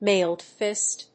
アクセントmáiled físt